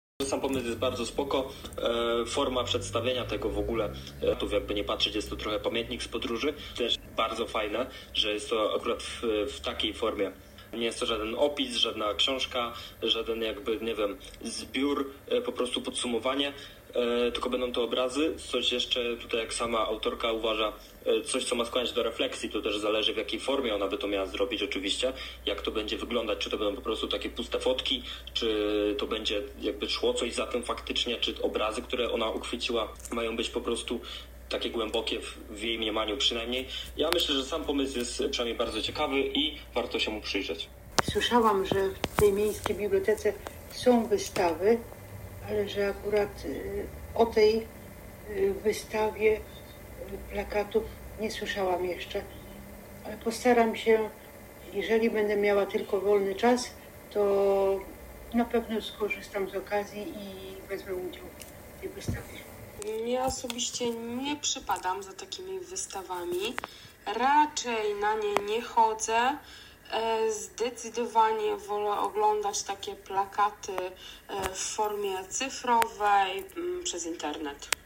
Zapytaliśmy studentów Uniwersytetu Opolskiego, co sądzą o takim pokazie: